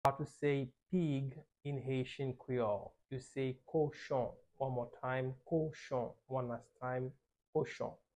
How to say Pig in Haitian Creole - Kochon pronunciation by a native Haitian Teacher
How-to-say-Pig-in-Haitian-Creole-Kochon-pronunciation-by-a-native-Haitian-Teacher.mp3